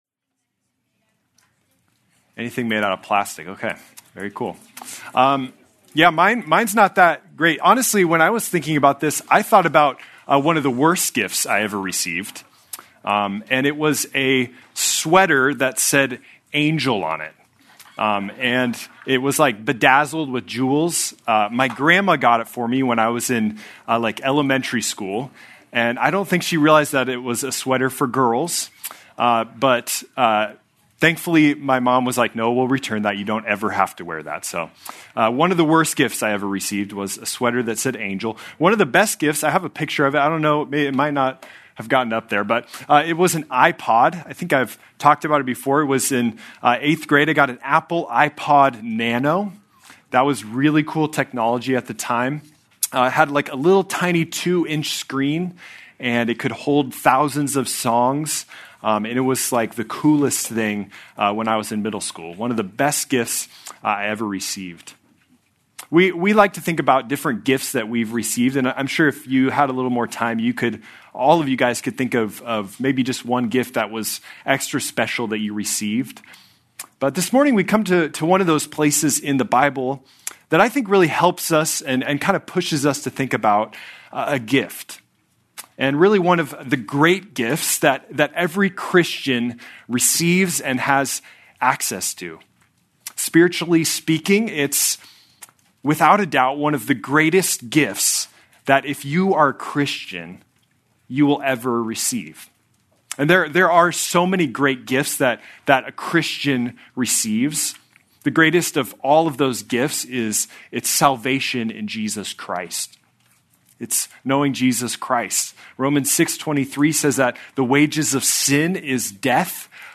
February 15, 2026 - Sermon